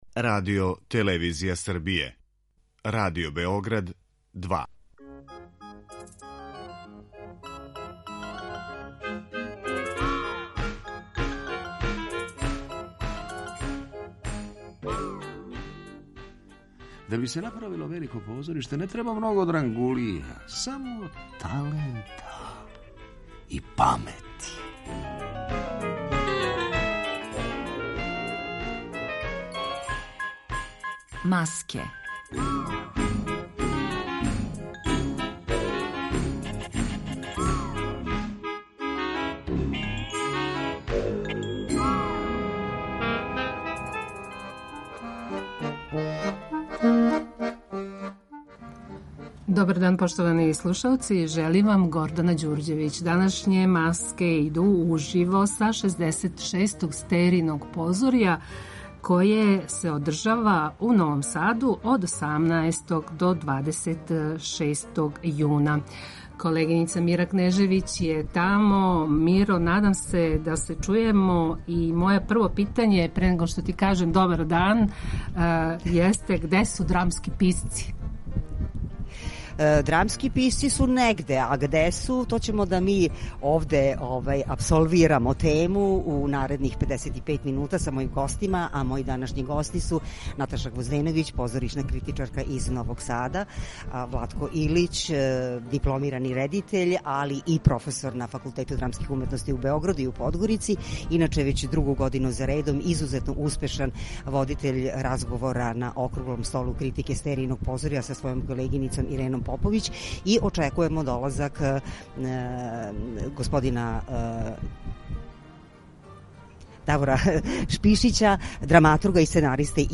Емисија иде уживо из фестивалског центра